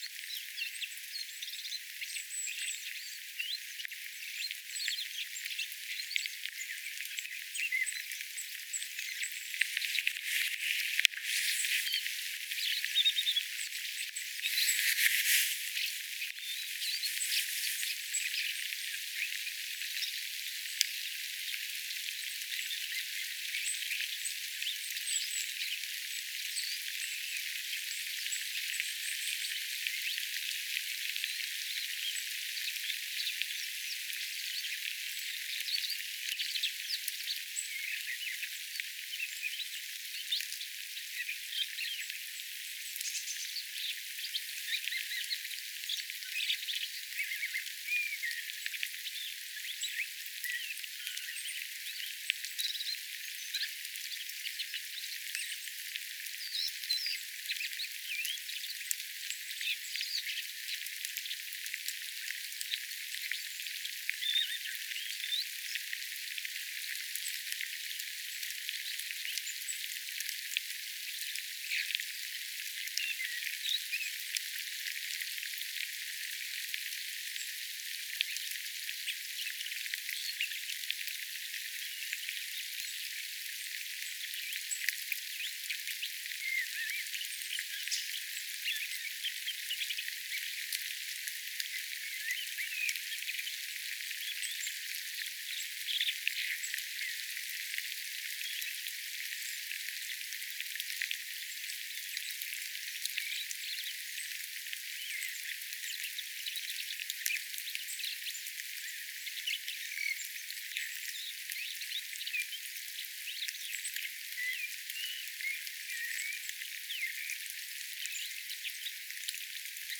Koko ajan se visertelylauloi.
Äänite: turdus sinensiksen visertelylaulua
pitkä hyräilysessio ihan metrien päästä äänitettynä
turdus_sinensiksen_visertelylaulua_lahelta_aanitettyna.mp3